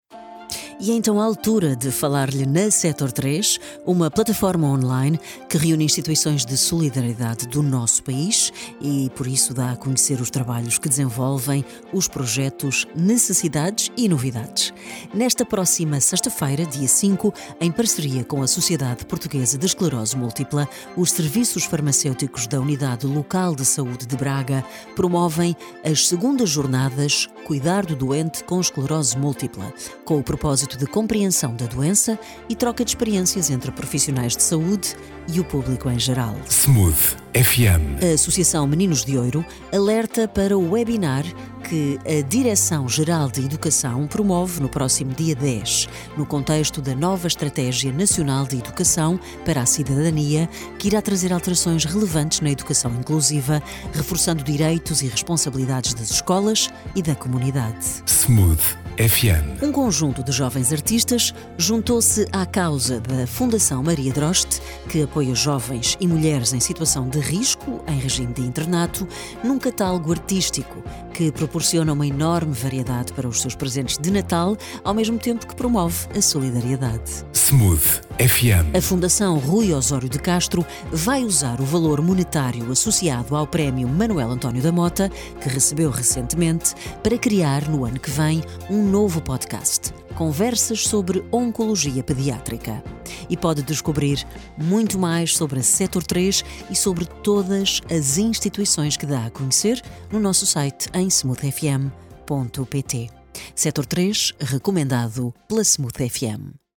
Apontamento rádio